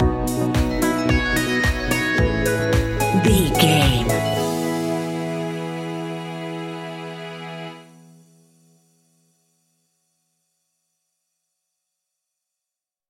Ionian/Major
peaceful
calm
electric piano
synthesiser
drums
strings
electro house